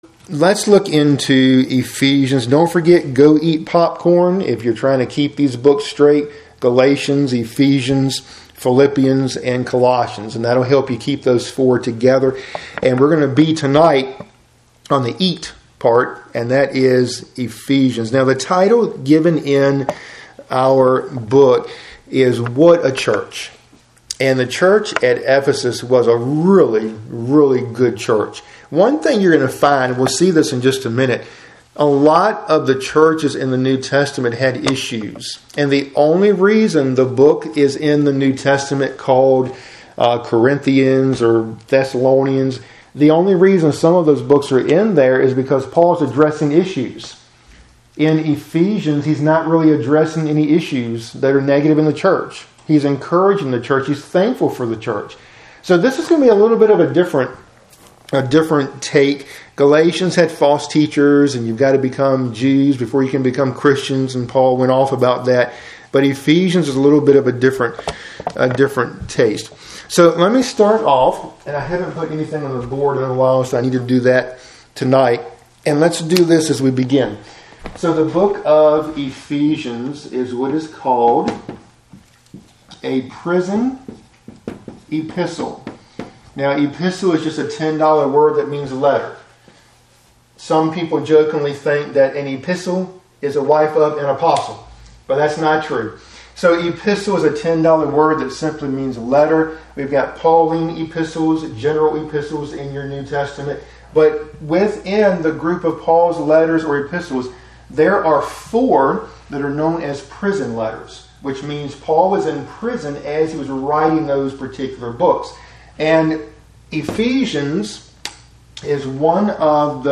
Midweek Bible Study – Lesson 56